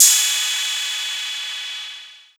TR808OH2.wav